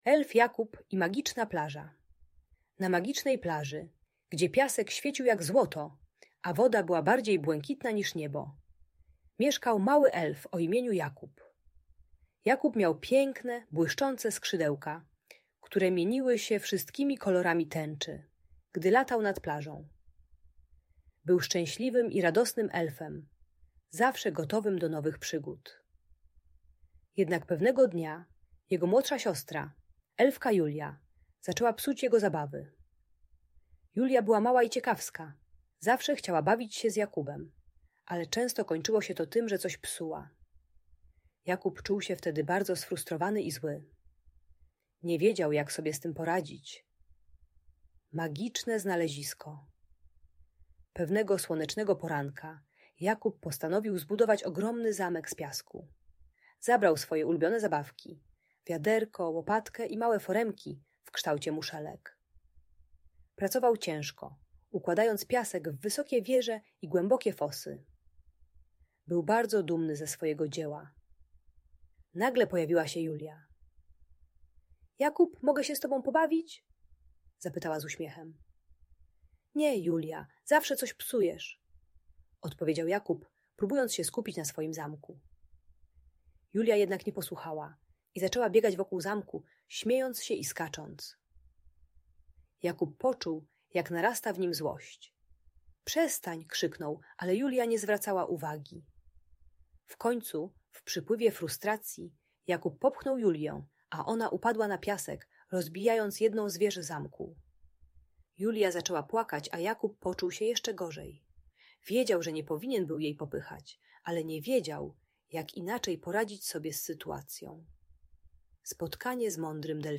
Elf Jakub i Magiczna Plaża - Urocza historia - Audiobajka